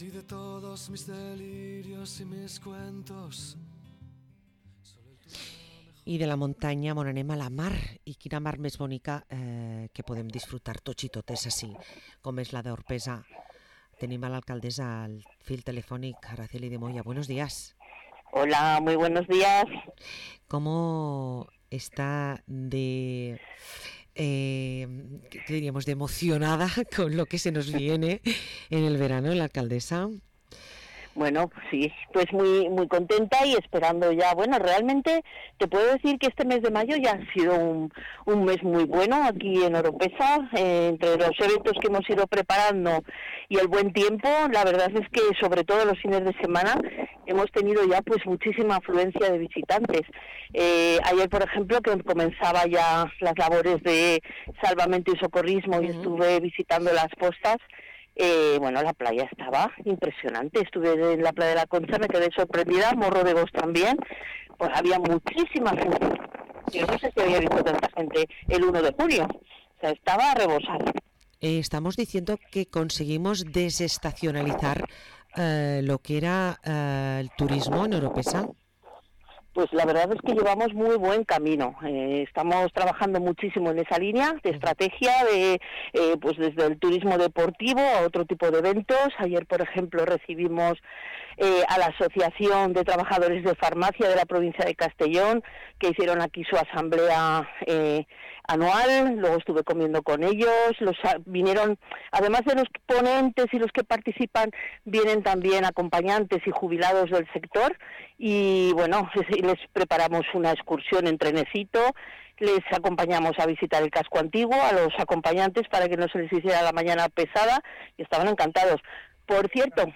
Parlem amb l´alcaldessa d´Orpesa, Araceli de Moya